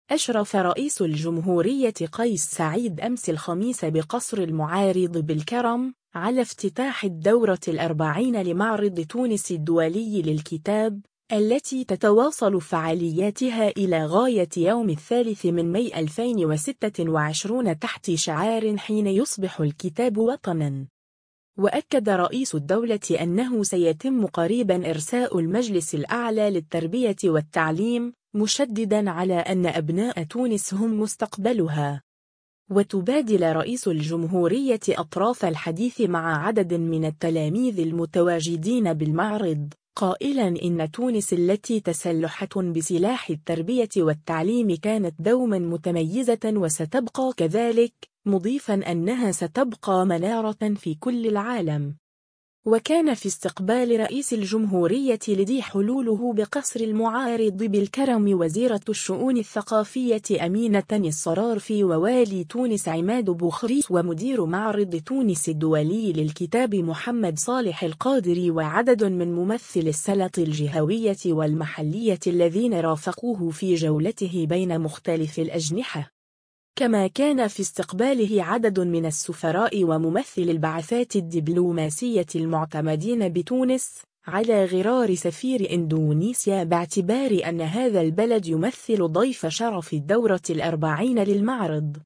أشرف رئيس الجمهورية قيس سعيد أمس الخميس بقصر المعارض بالكرم، على افتتاح الدورة الأربعين لمعرض تونس الدولي للكتاب، التي تتواصل فعالياتها إلى غاية يوم 3 ماي 2026 تحت شعار “حين يصبح الكتاب وطنا”.
وتبادل رئيس الجمهورية أطراف الحديث مع عدد من التلاميذ المتواجدين بالمعرض، قائلا إنّ تونس التي تسلحت بسلاح التّربية والتعليم كانت دوما متميزة وستبقى كذلك، مضيفا أنّها ستبقى منارة في كل العالم.